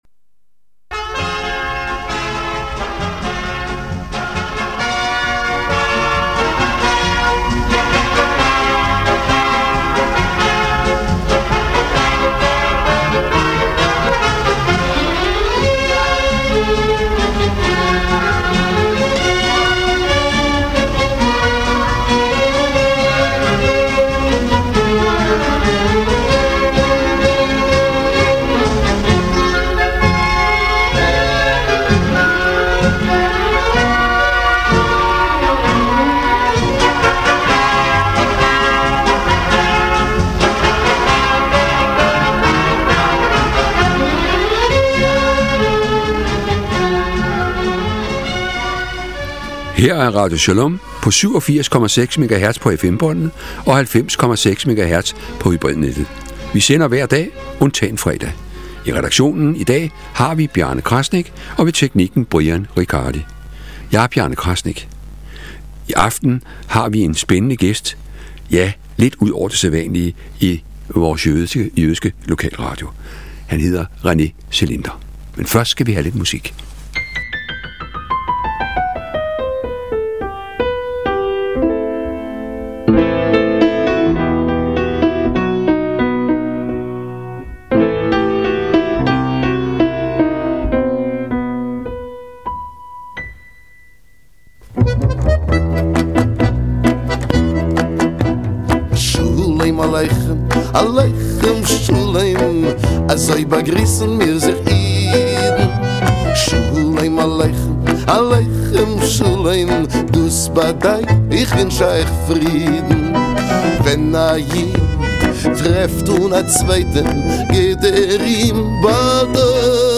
Beskrivelse: Interview